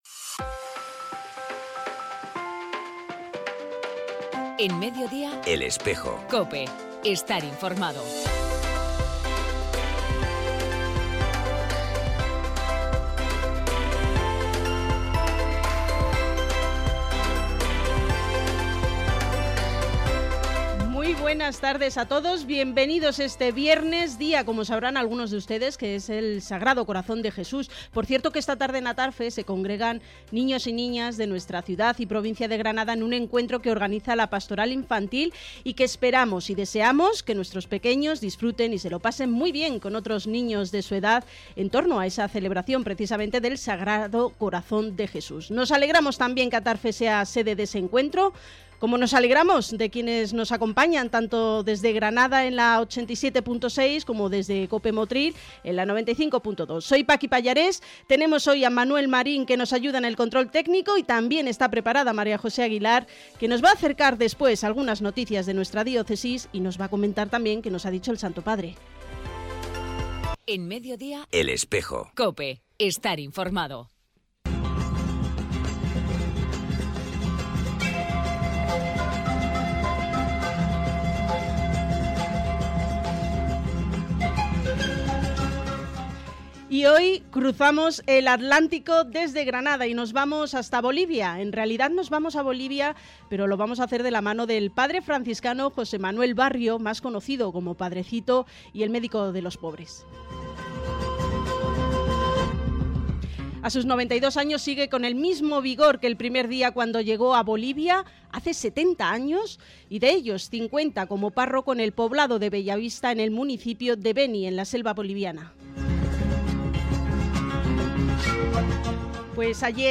Entrevista
Audio disponible del programa Audio disponible del programa emitido el viernes día 8 para COPE Granada y COPE Motril, realizado por el Secretariado de Medios de Comunicación Social del Arzobispado.